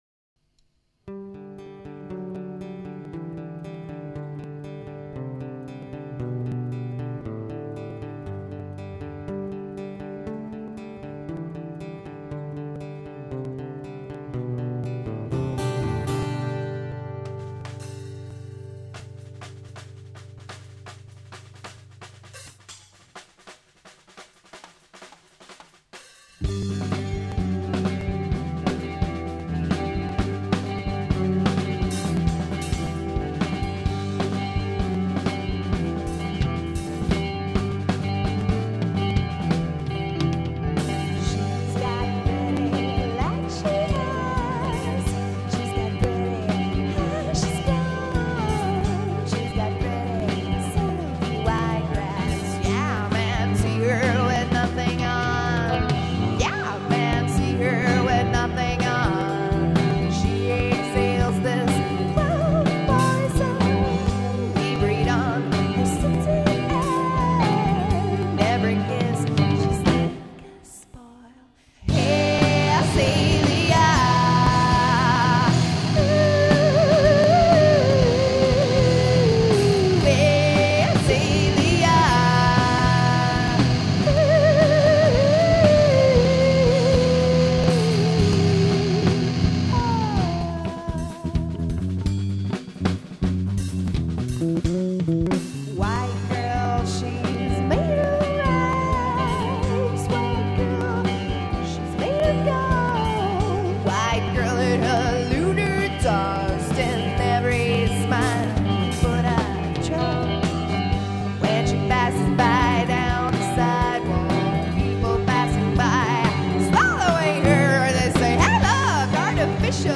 Gravada no P.G. Studio entre 1.9.99 e 14.9.99